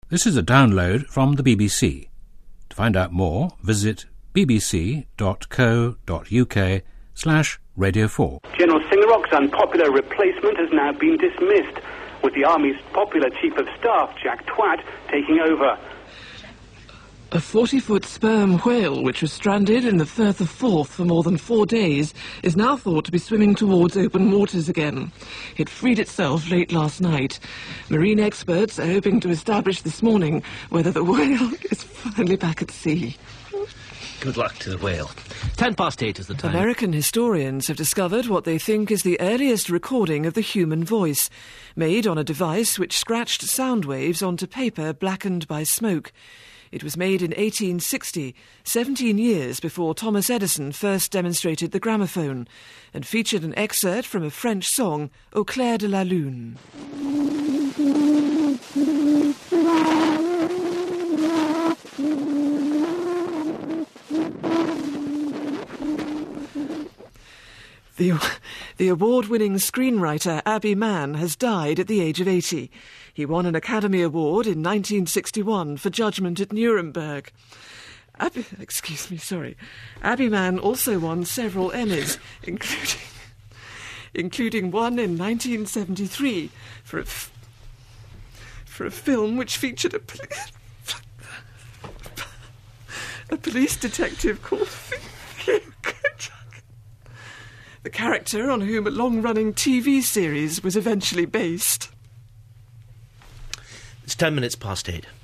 Charlotte Green Gets The Giggles
It's the worst thing that can happen to a much-loved newsreader. Anything can set you off and once you have started to laugh, trying to stop makes it worse.
charlotte-green-news-giggles.mp3